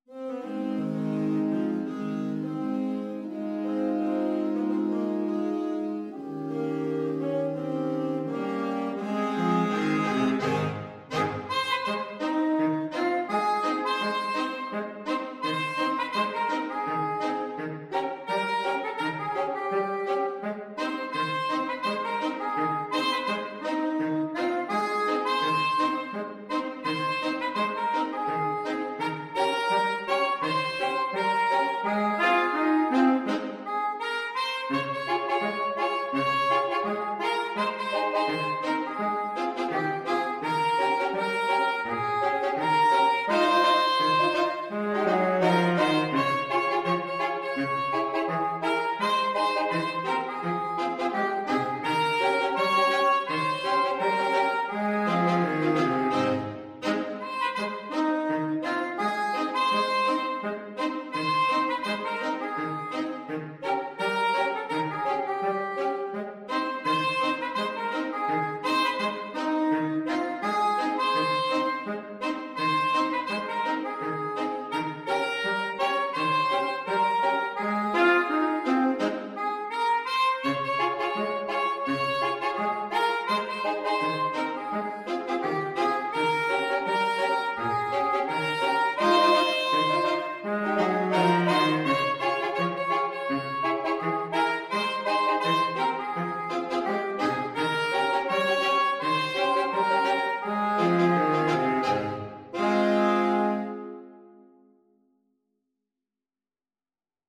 Saxophone Quartet version
is a gospel song.
2/2 (View more 2/2 Music)
Calmly =c.84